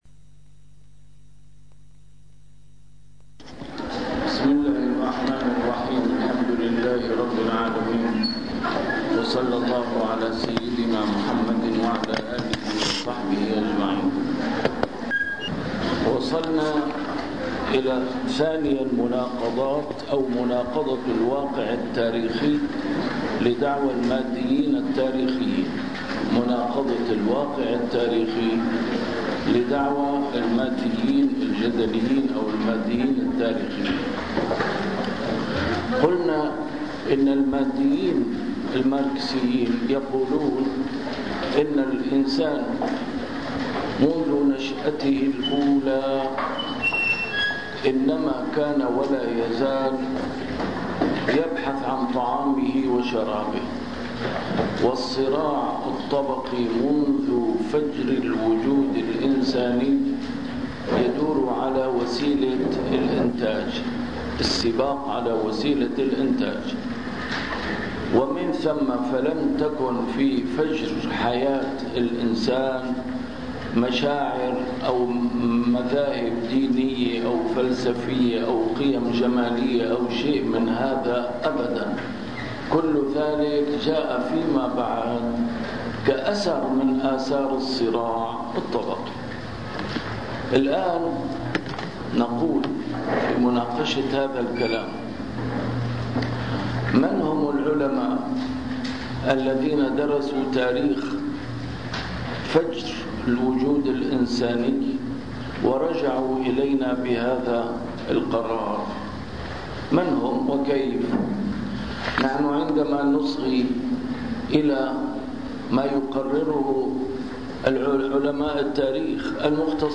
المحاضرة الرابعة: تتمة نقد المادية التاريخية 2